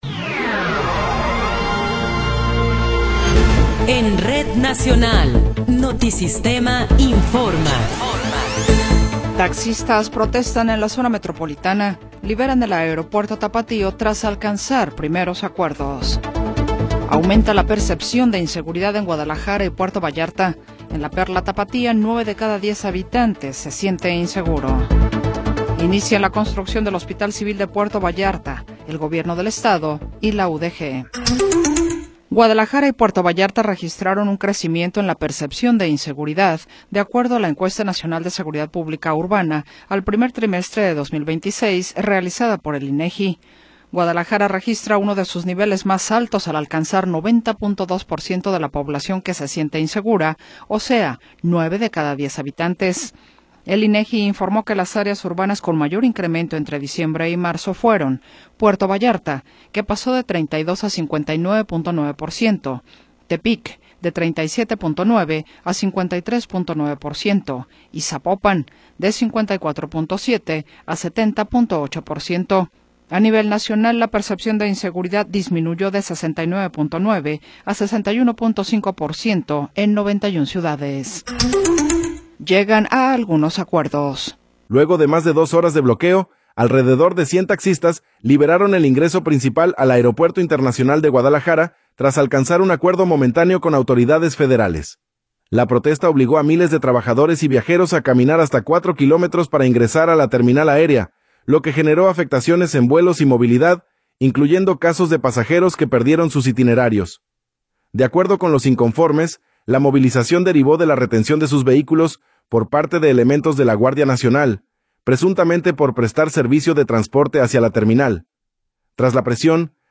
Noticiero 20 hrs. – 24 de Abril de 2026
Resumen informativo Notisistema, la mejor y más completa información cada hora en la hora.